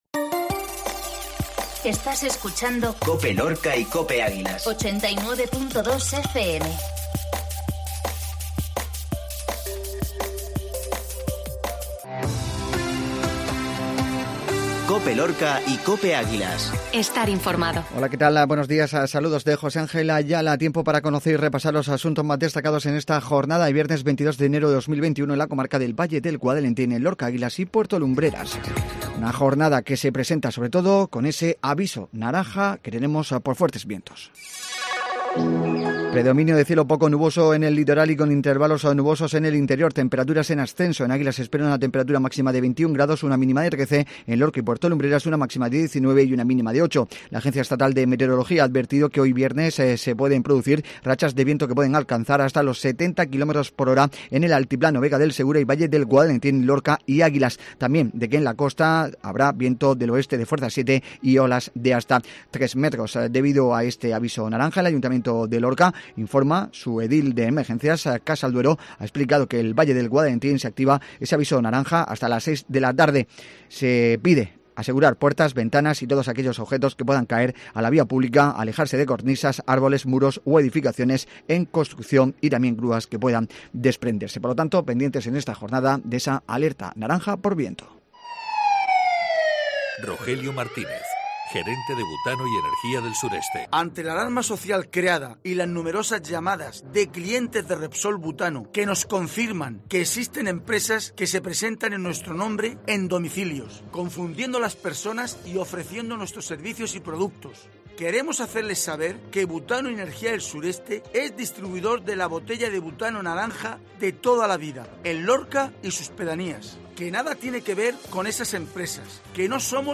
INFORMATIVO MATINAL VIERNES